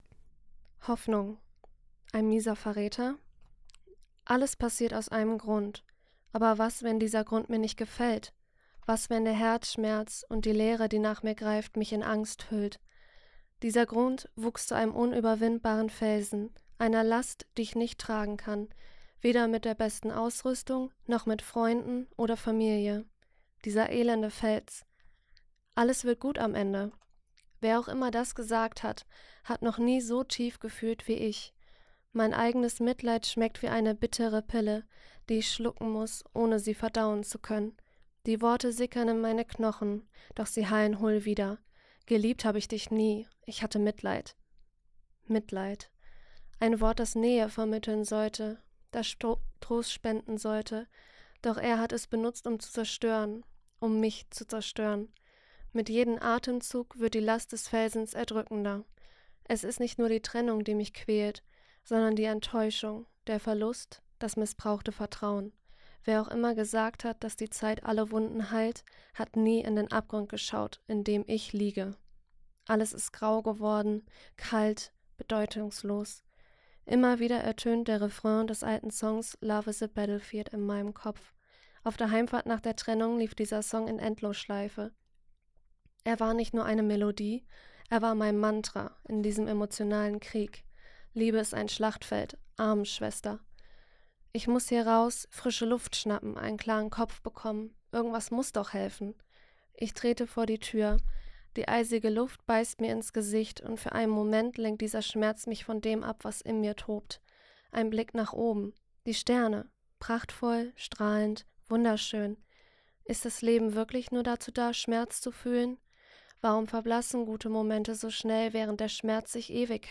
vorgelesen